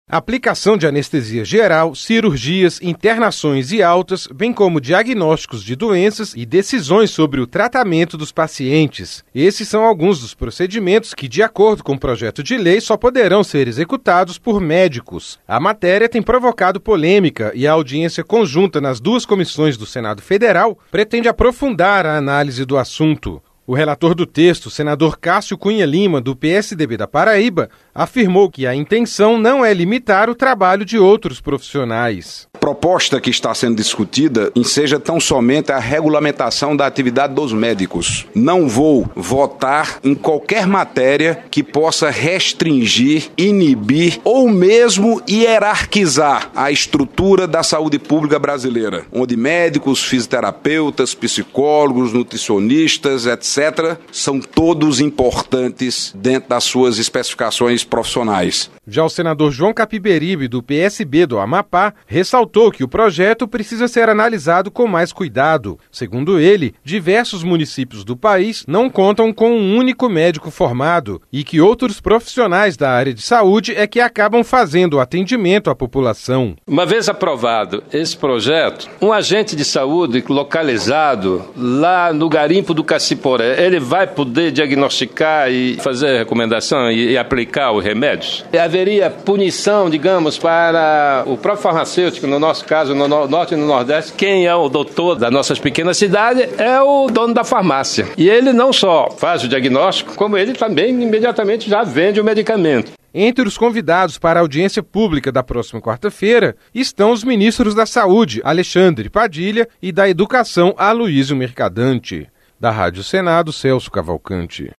Senador João Capiberibe
Senador Cássio Cunha Lima